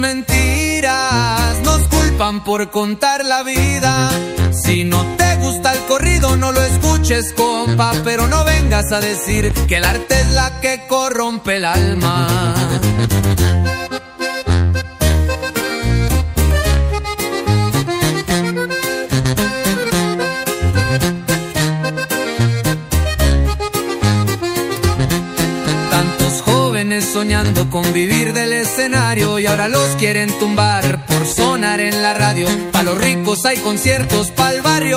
Alternative Hip-Hop Rap
Жанр: Хип-Хоп / Рэп / Альтернатива